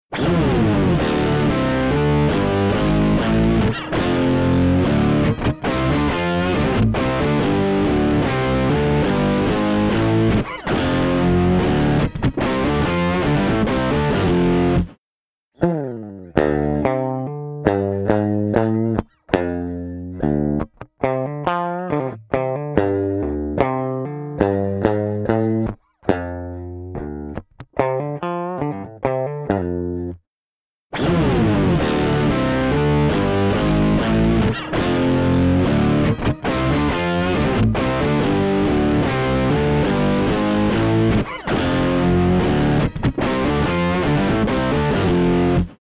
パワフルなディストーション＆サチュレーション・エンジン
CrushStation | Guitar | Preset: Bottom Feeder
CrushStation-Eventide-Guitar-Bottom-Feeder.mp3